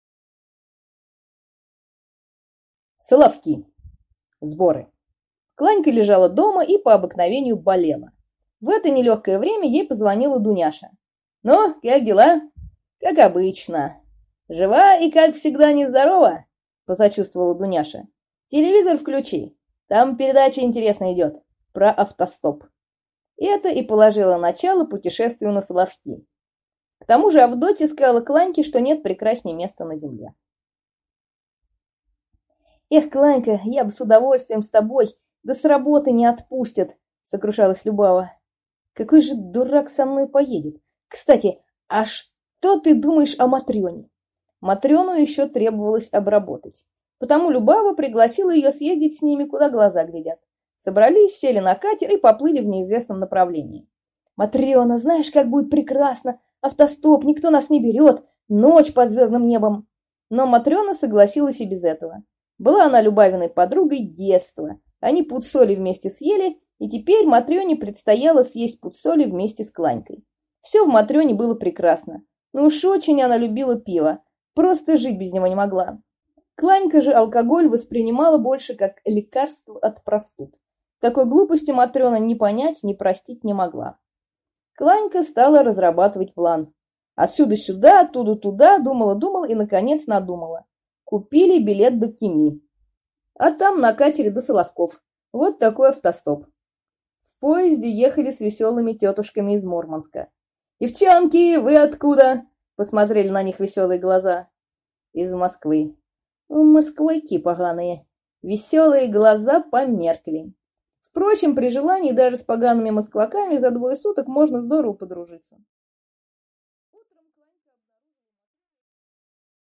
Аудиокнига 3. Соловки, 90-е | Библиотека аудиокниг